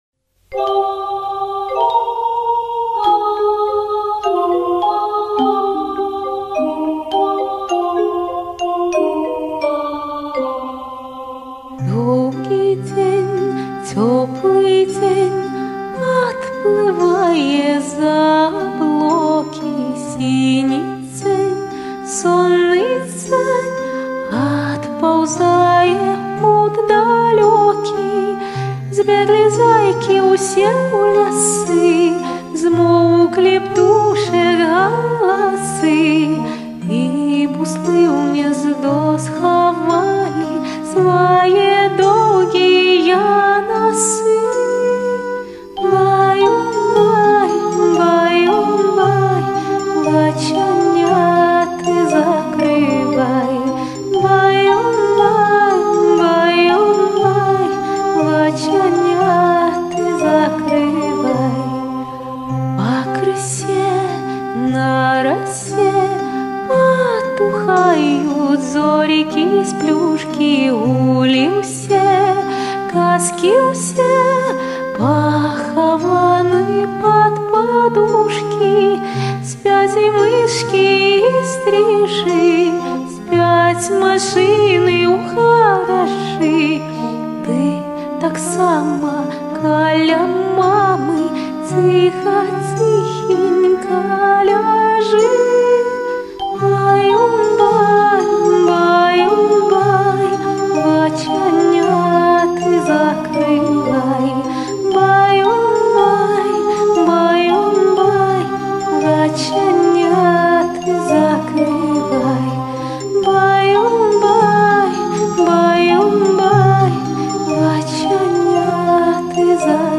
(белорусская колыбельная песня)